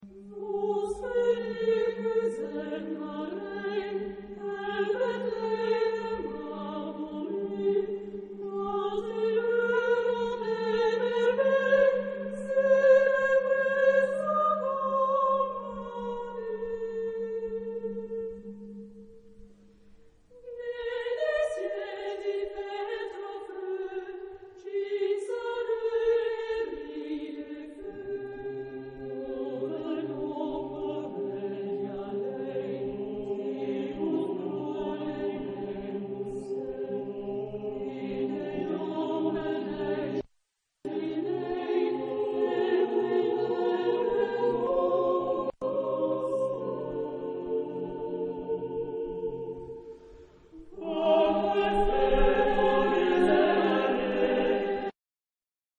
SATB (div.) (4 voices mixed) ; Full score.
Partsong. Folk music. Christmas song.